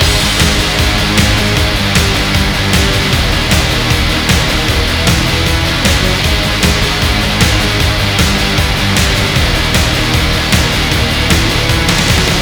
minigun_spin.wav